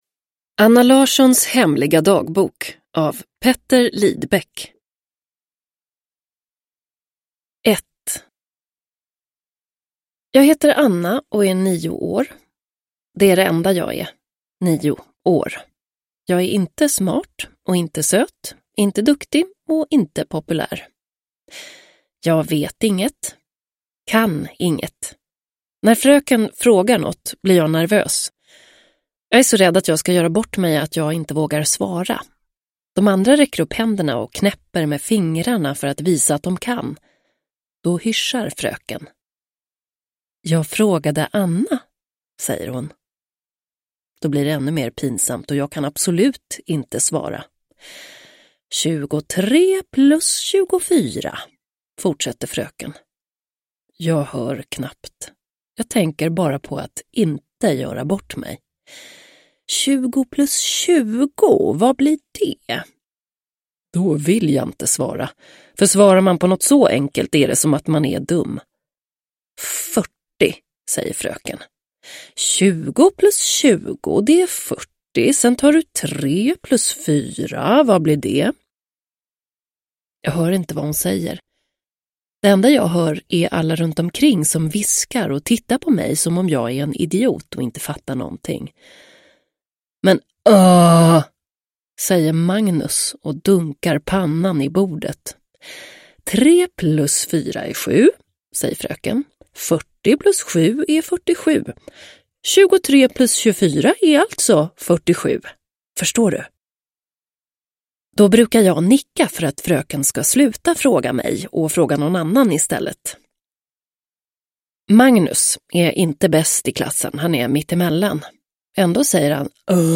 Anna Larssons hemliga dagbok – Ljudbok – Laddas ner